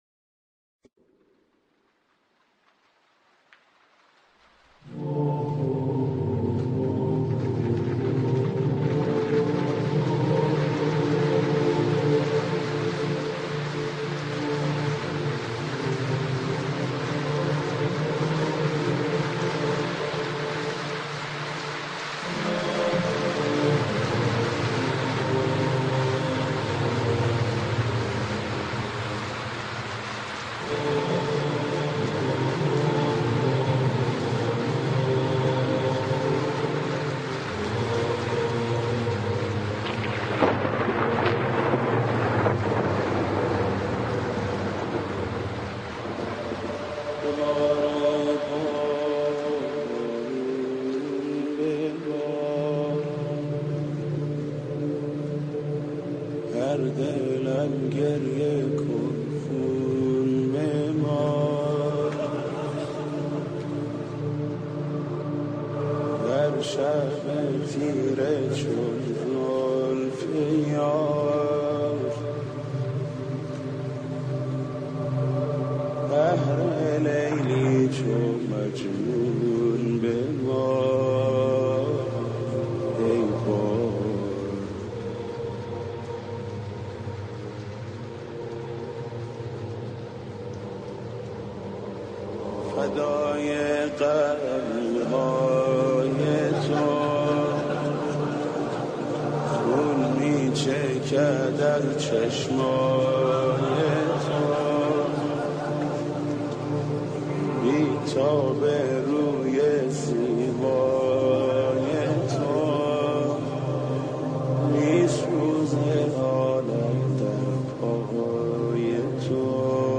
پادکست | نوحه‌خوانی محمود کریمی در وفات حضرت زینب(س)
در این پادکست، نوحه «ببار ای بارون» را با صدای حاج محمود کریمی به مناسبت وفات حضزت زینب(س) می شنوید.